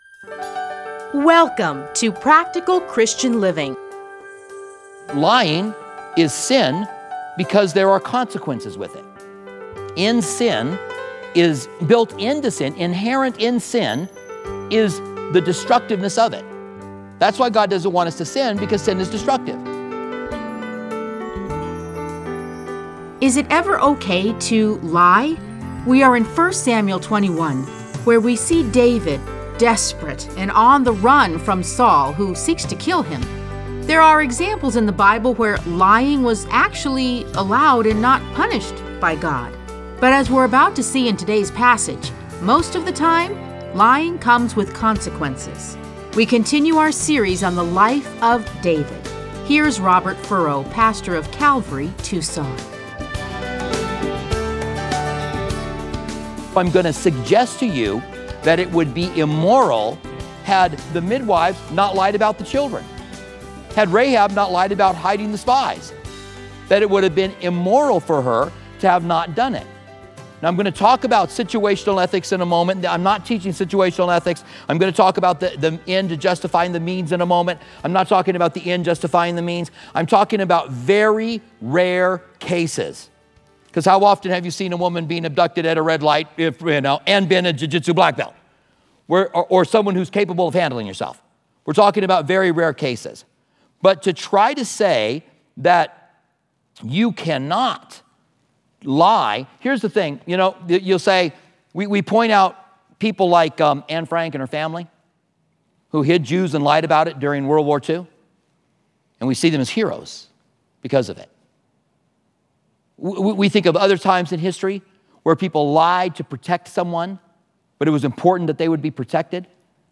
Listen to a teaching from 1 Samuel 21:1-15.